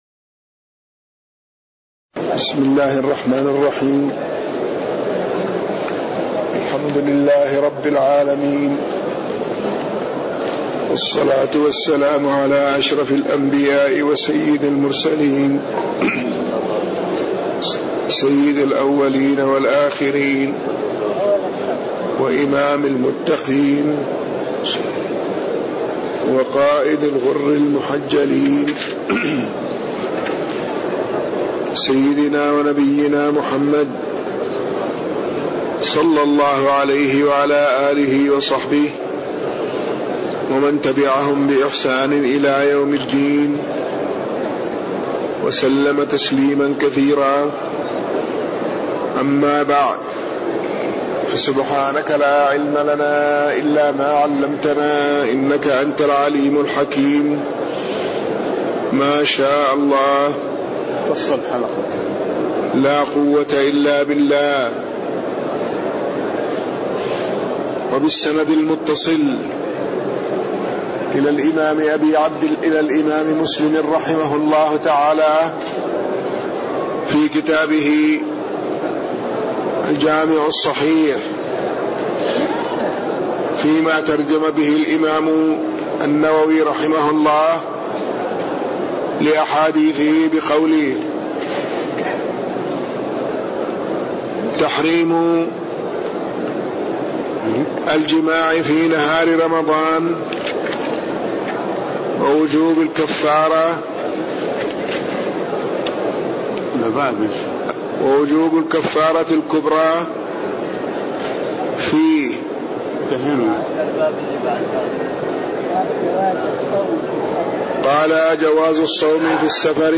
تاريخ النشر ١٦ رمضان ١٤٣٣ هـ المكان: المسجد النبوي الشيخ